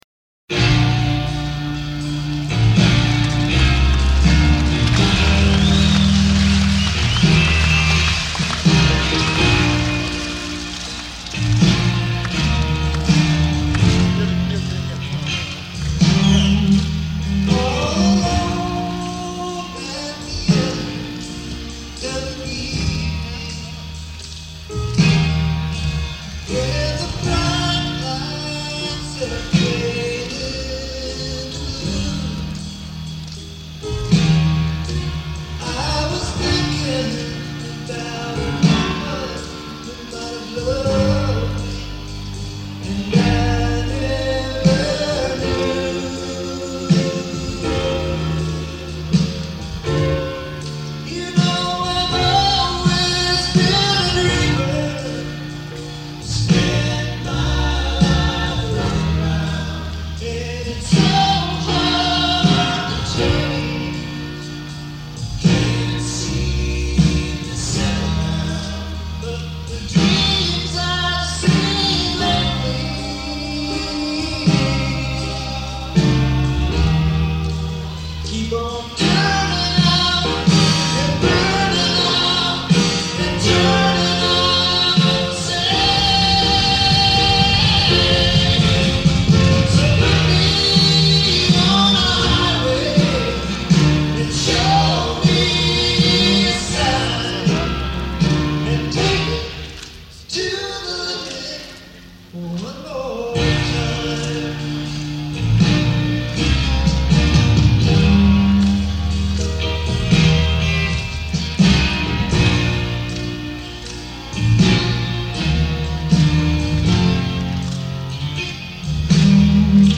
Scandinavium, Gothenburg